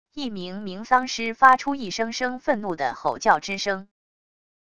一名名丧尸发出一声声愤怒的吼叫之声wav音频